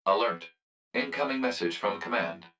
042_Incoming_Message.wav